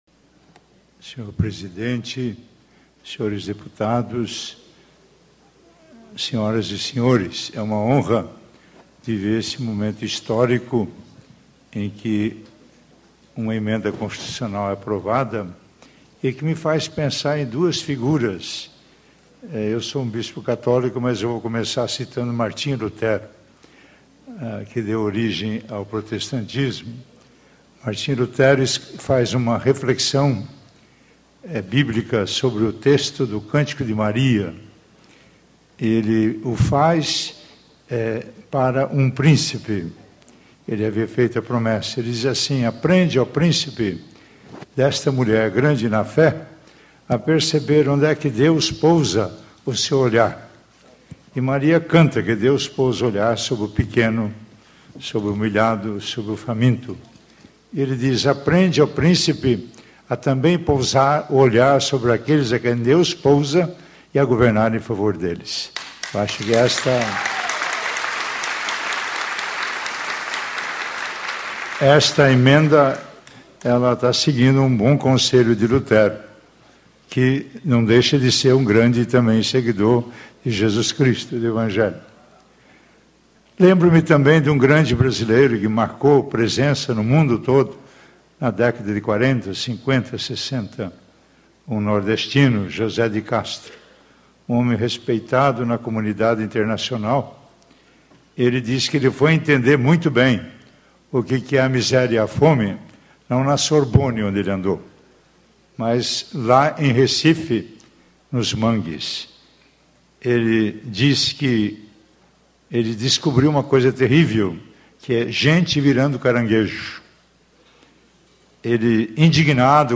Discursos e Palestras
Dom Mauro Morelli, Presidente do Consea - MG - Conselho Estadual de Segurança Alimentar - Solenidade de Promulgação da Emenda à Constituição 86/2011 que inclui a erradicação da pobreza entre os objetivos prioritários do Estado de Minas Gerais